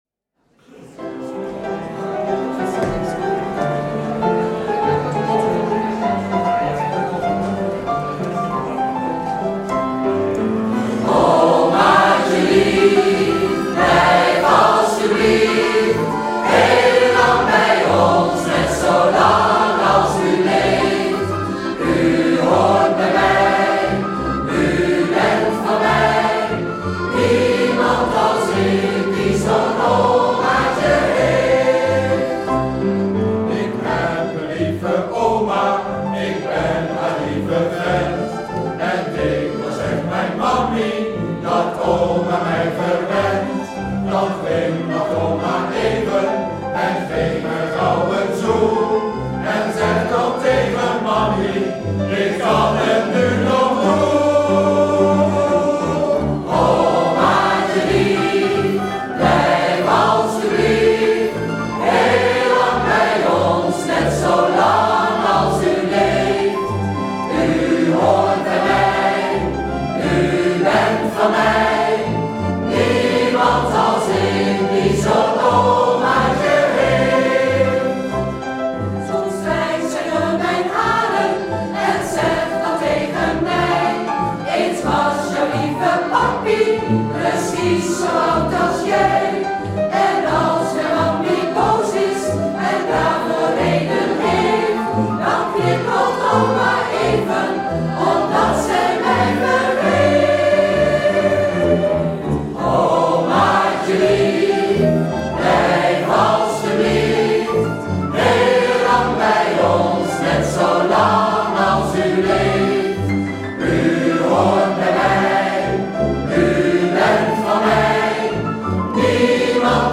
Piano
Met meeslepende live-opnames!
De (technische) kwaliteit loopt nogal uiteen.